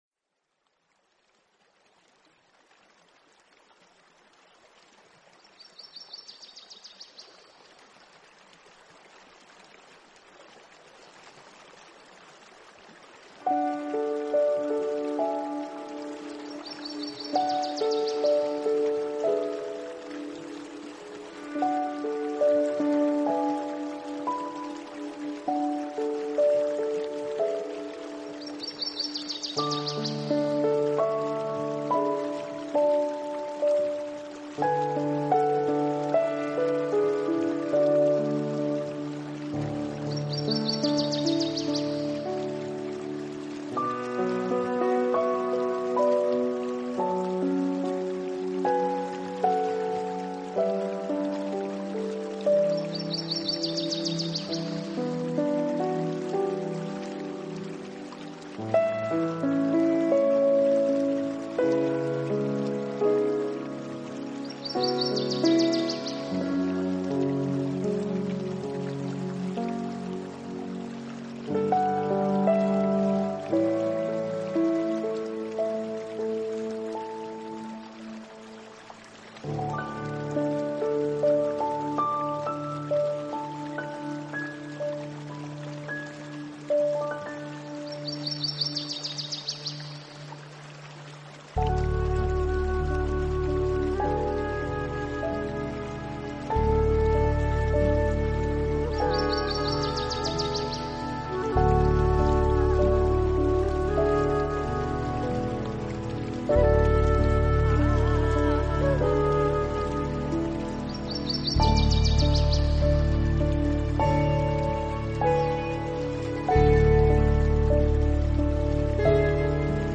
冥想音乐｜The River Flows（10分钟）.mp3